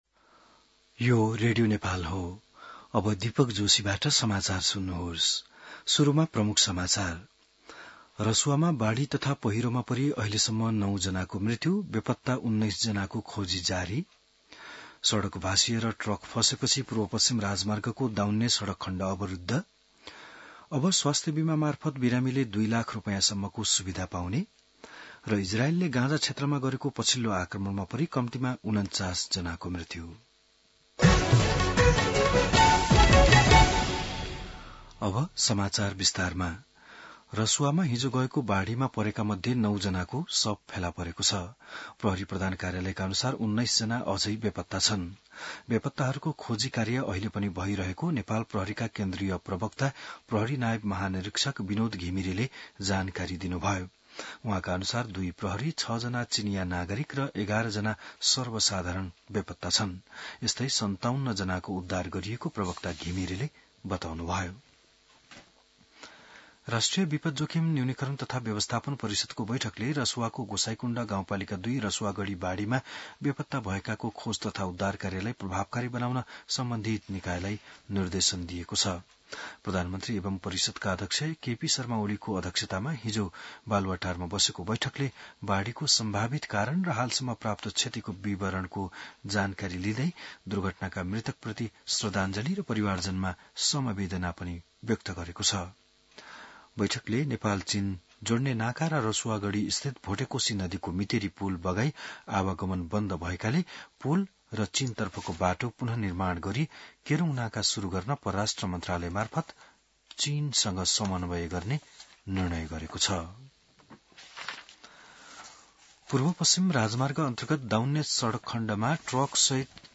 बिहान ९ बजेको नेपाली समाचार : २५ असार , २०८२